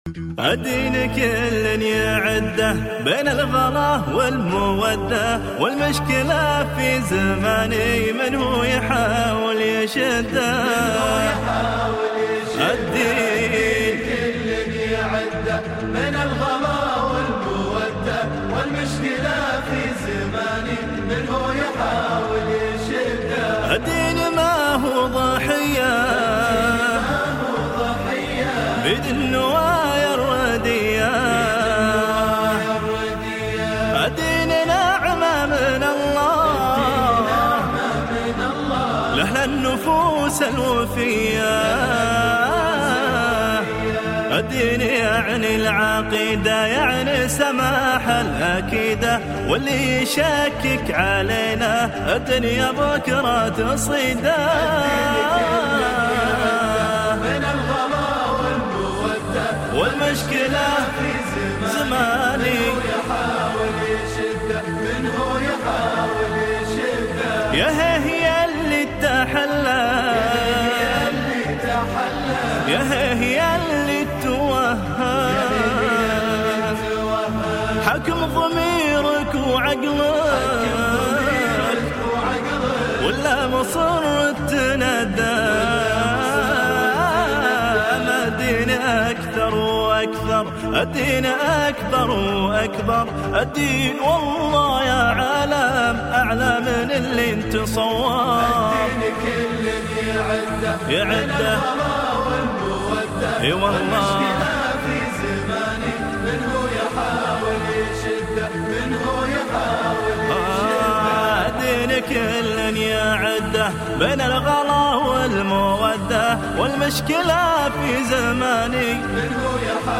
<< هالنشيدة ناقصها طق و ـ